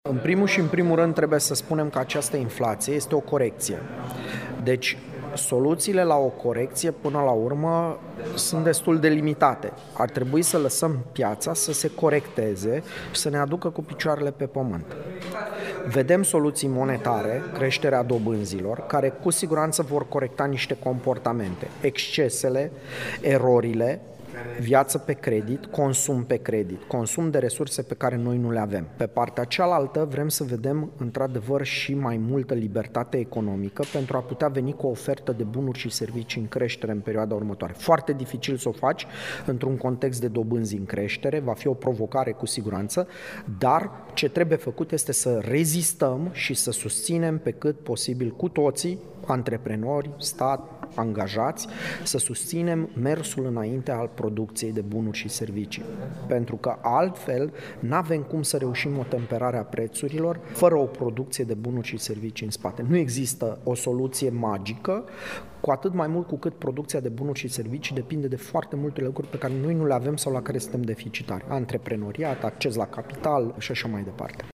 Aseară, la Camera de Comerț Iași s-a desfășurat conferința „Economia în vremea inflației”.